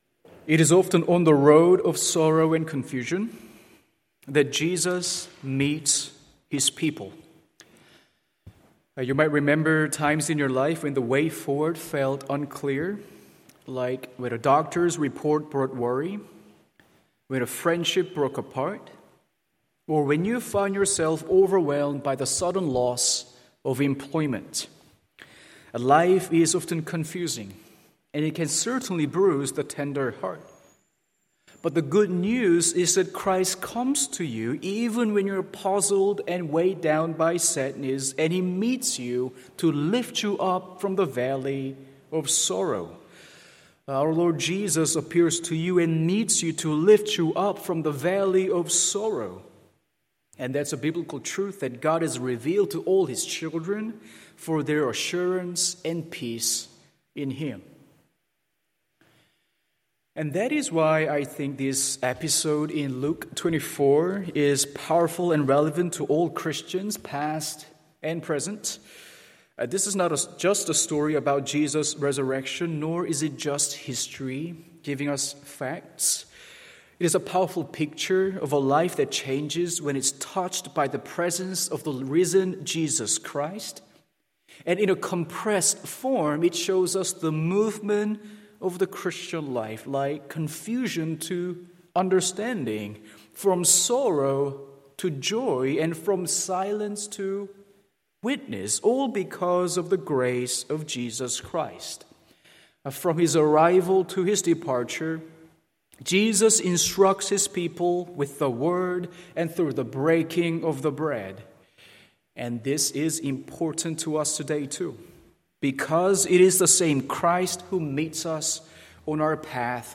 Morning Service Luke 24:13-35…